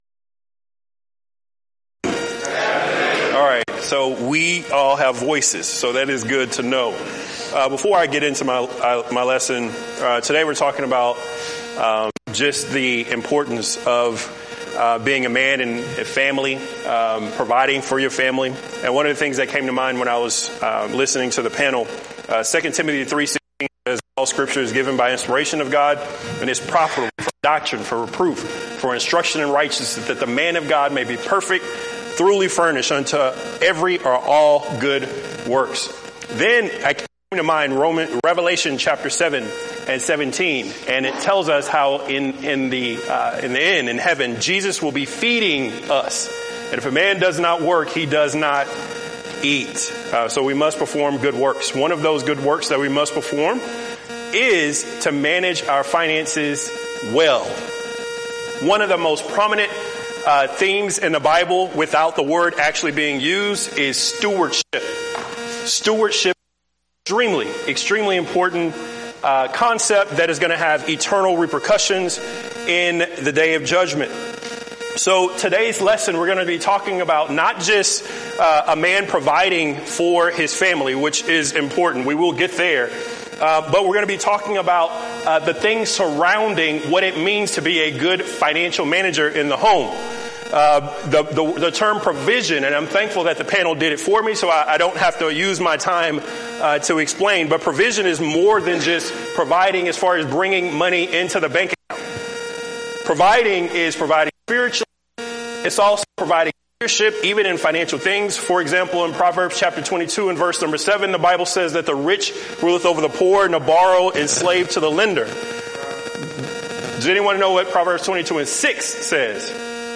Event: 6th Annual BCS Men's Development Conference